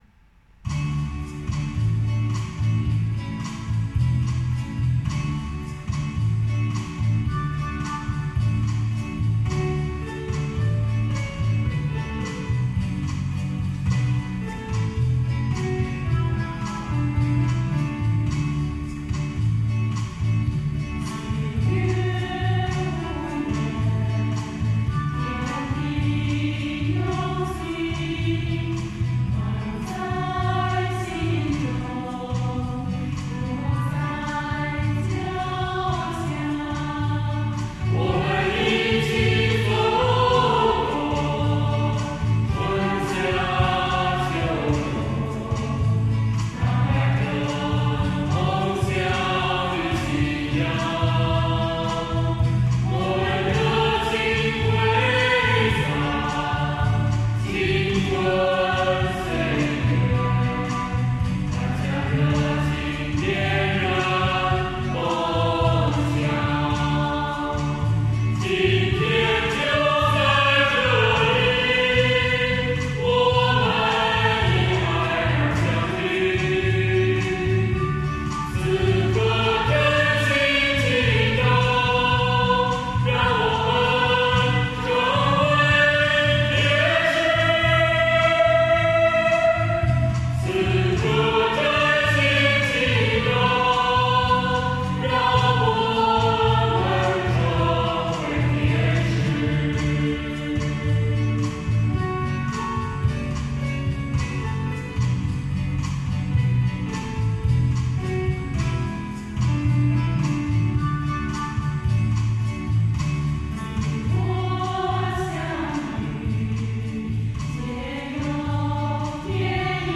【原创圣歌】